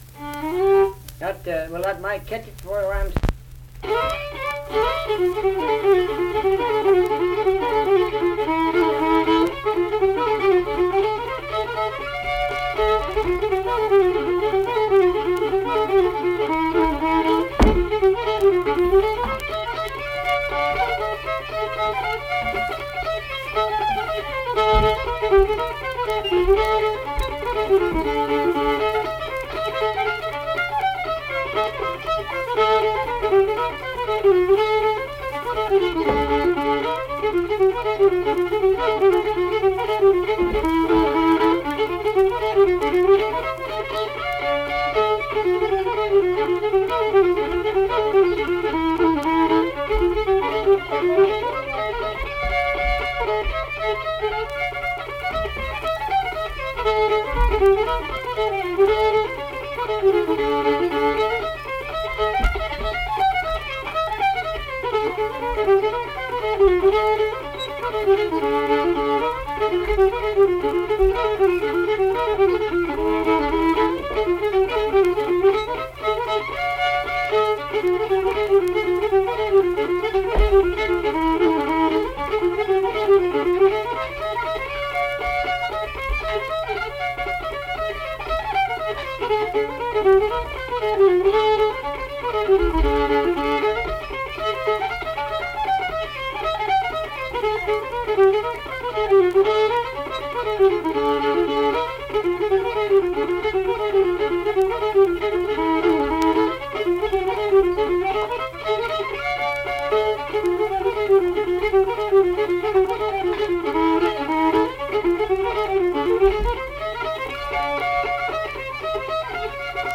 Unaccompanied fiddle music
Instrumental Music
Fiddle
Flatwoods (Braxton County, W. Va.), Braxton County (W. Va.)